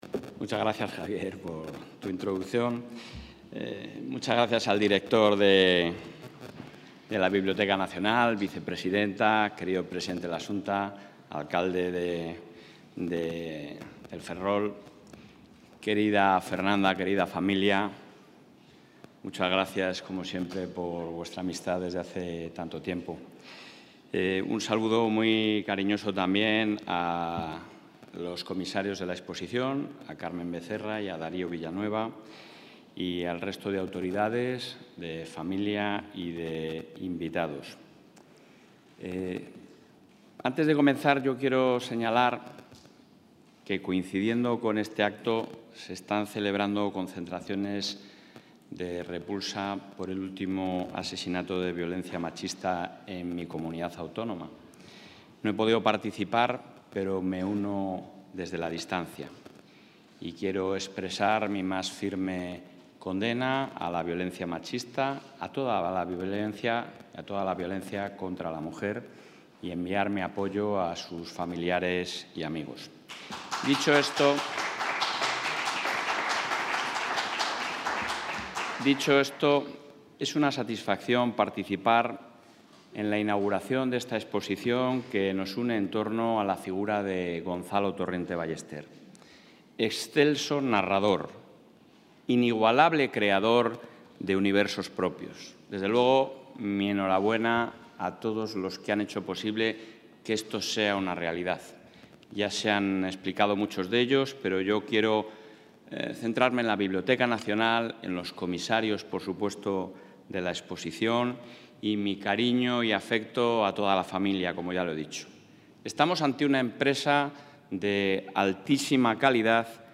El presidente de la Junta de Castilla y León, Alfonso Fernández Mañueco, ha asistido hoy en la Biblioteca Nacional de...
Intervención del presidente de la Junta.